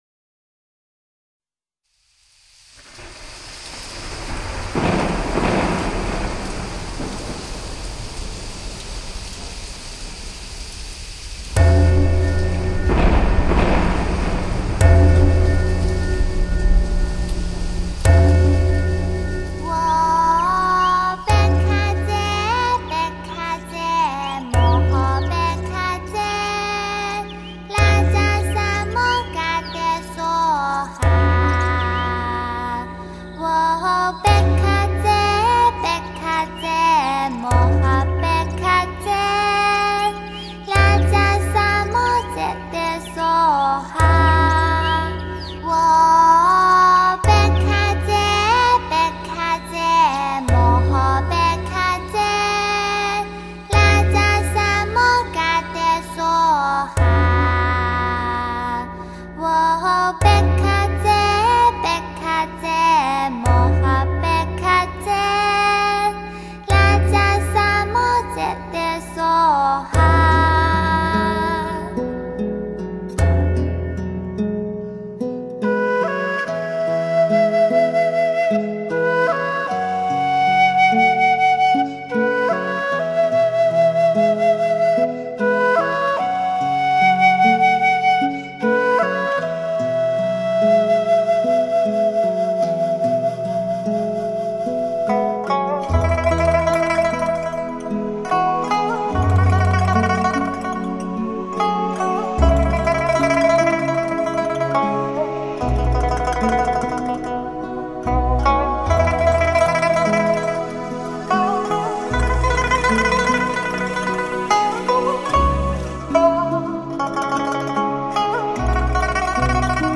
“自然声响”运用得宜，不落俗套。